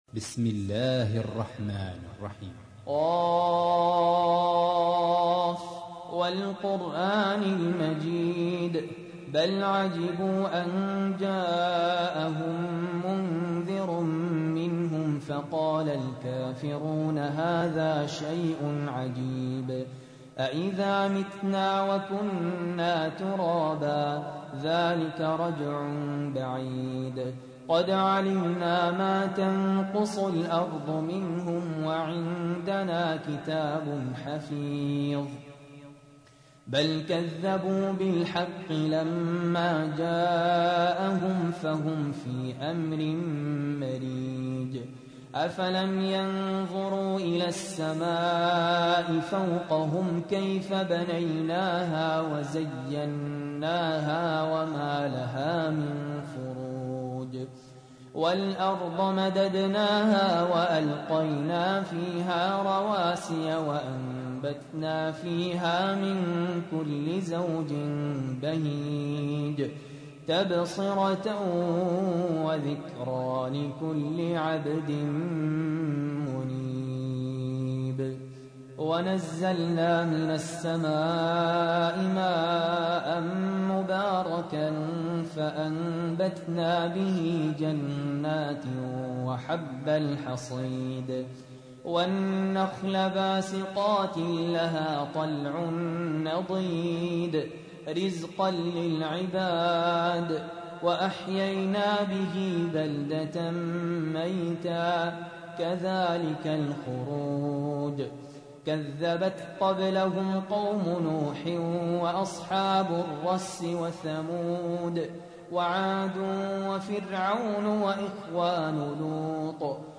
تحميل : 50. سورة ق / القارئ سهل ياسين / القرآن الكريم / موقع يا حسين